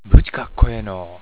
ためになる広島の方言辞典 は．
広島県内でも、地域によって「ぶち」「ぶり」等の変化がある。
buchi.wav